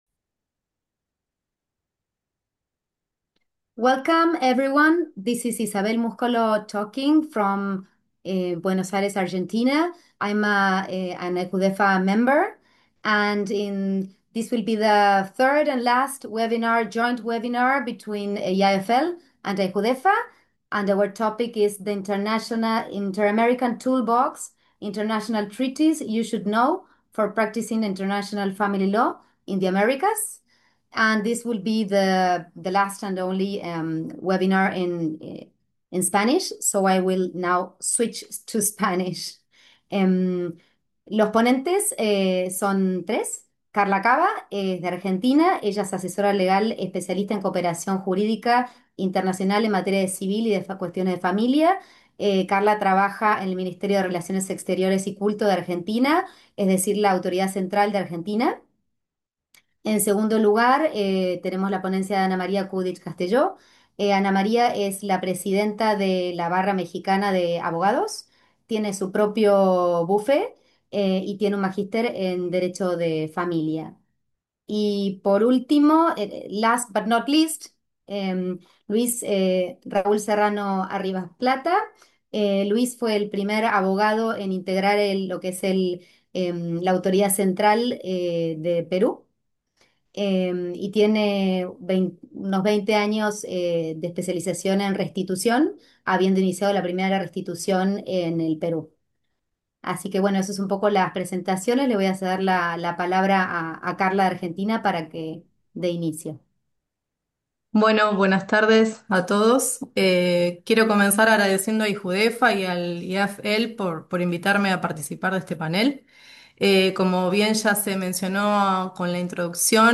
IAFL-AIJUDEFA webinar series: Hot Topics in Interamerican Family Law - PANEL III